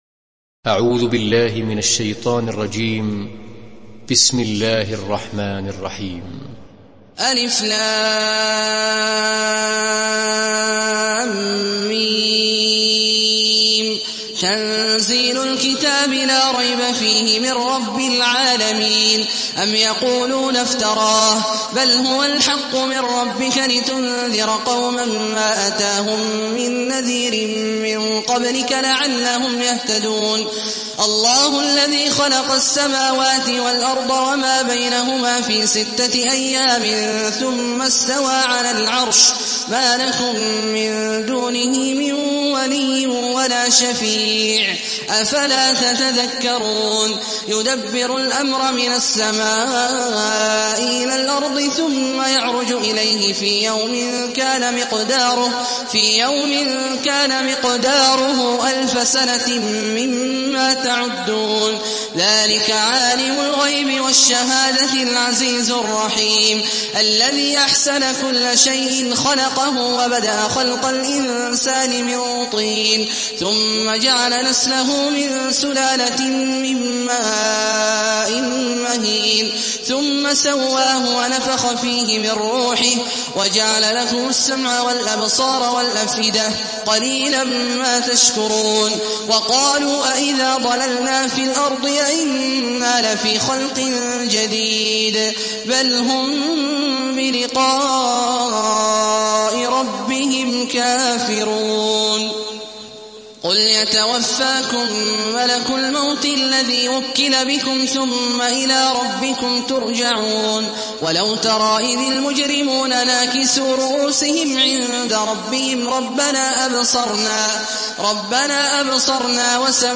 Surah আস-সাজদা MP3 by Abdullah Al-Juhani in Hafs An Asim narration.
Murattal Hafs An Asim